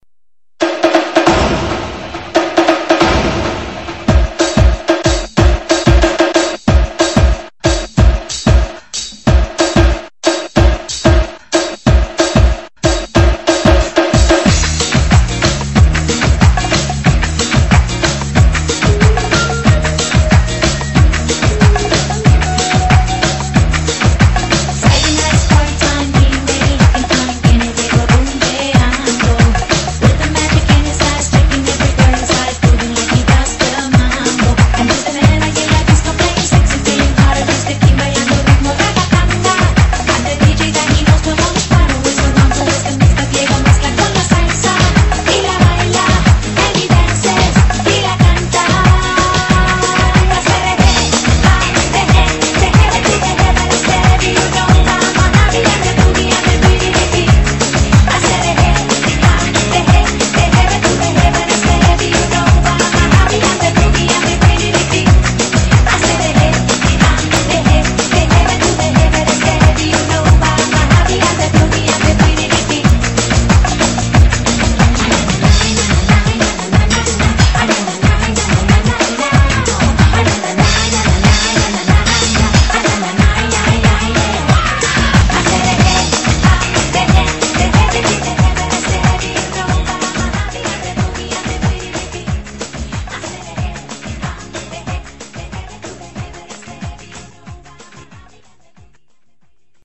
BPM93--1
Audio QualityPerfect (High Quality)